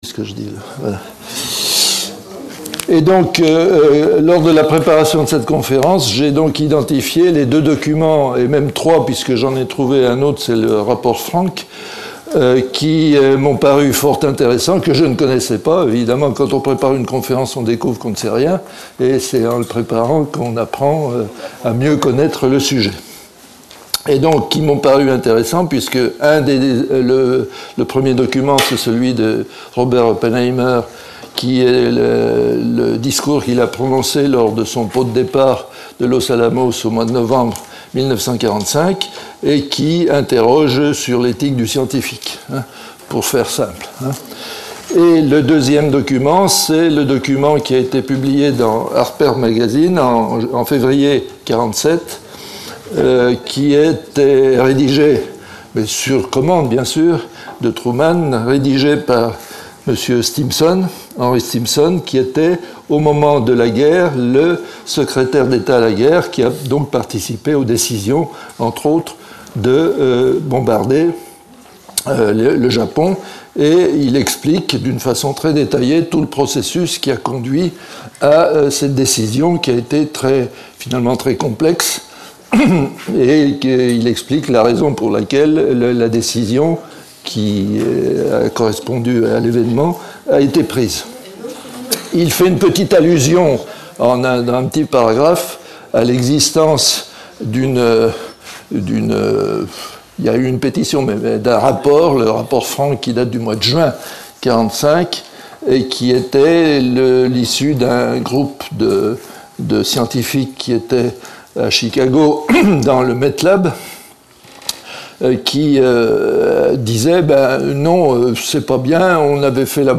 Conférence débat sur l'éthique, la stratégie et la géopolitique de l'arme atomique en 2017